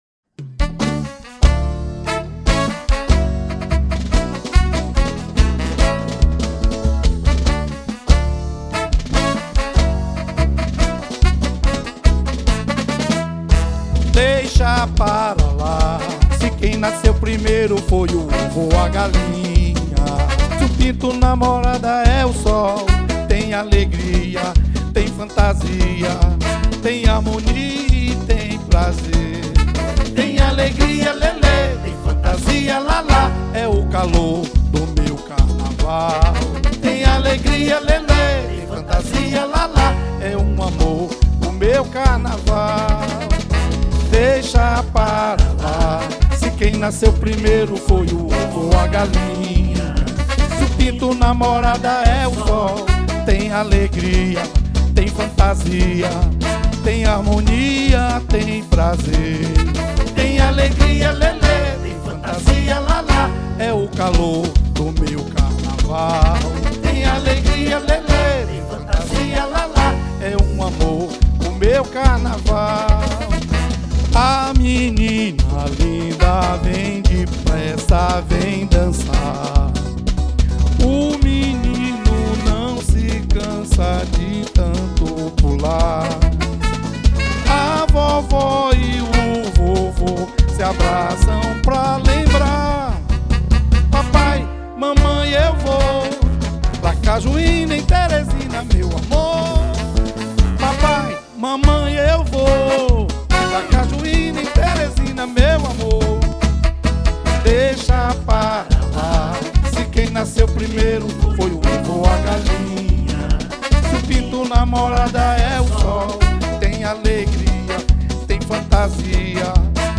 03:05:00   Frevo